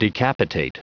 Prononciation du mot decapitate en anglais (fichier audio)
Prononciation du mot : decapitate
decapitate.wav